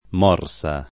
[ m 0 r S a ]